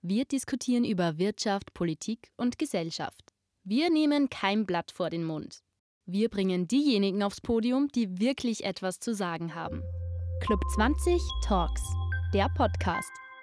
Podcast Intro